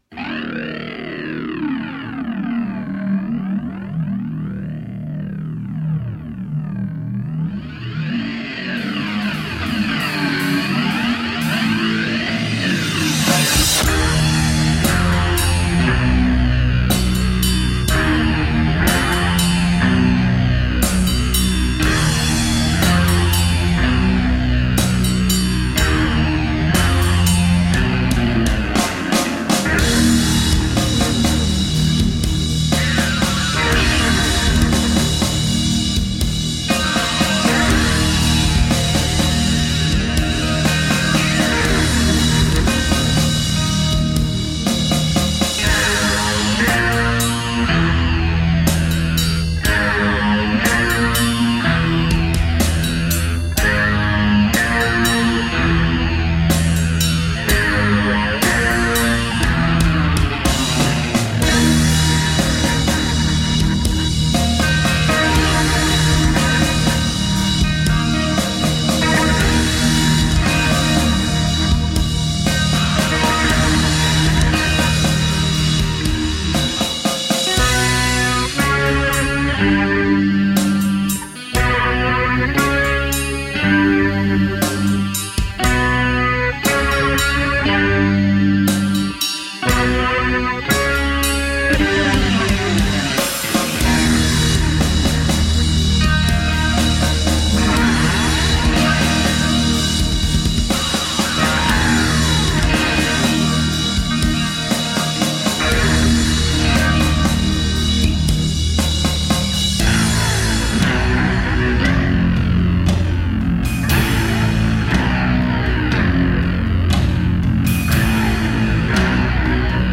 Hard, wild, thrashing, punk-edged heavy metal.
Tagged as: Hard Rock, Metal, Intense Metal